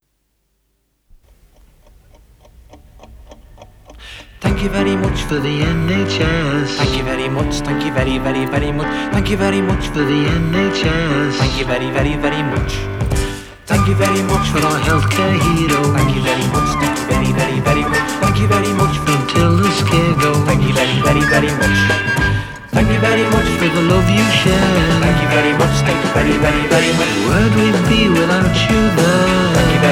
• Oldies